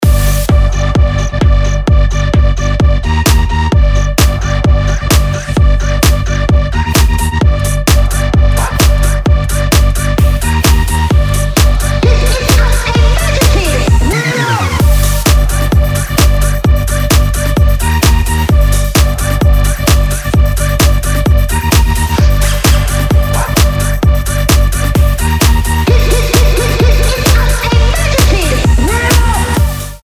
Крутая басовая хаус-музыка.